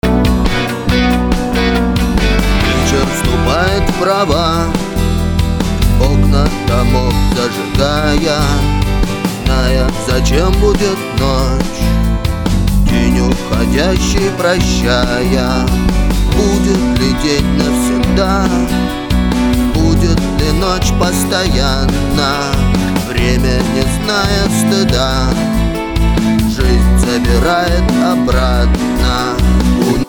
сведение вокала, подскажите!?
Свожу вокал, подскажите как на ваш взгляд лучше это сделать? чего не хватает?